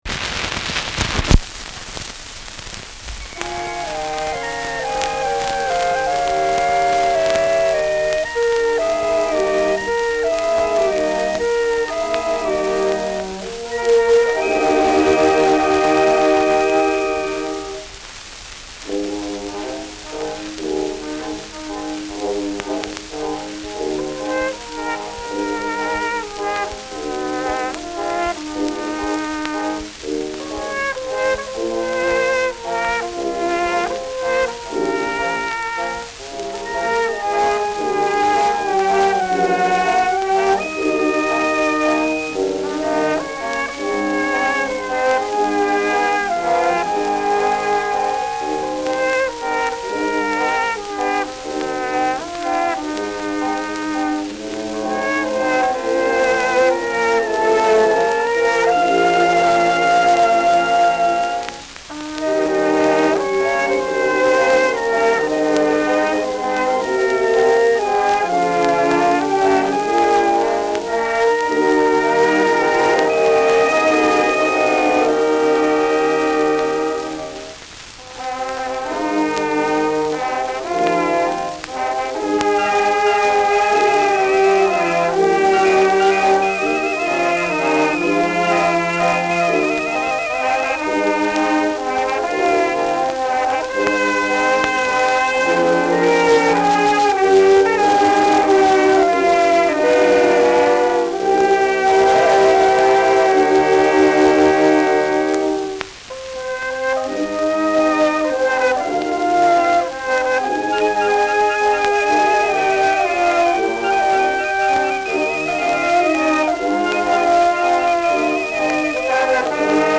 Schellackplatte
Abgespielt : Erhöhtes Grundrauschen : Teils leicht verzerrt : leichtes Leiern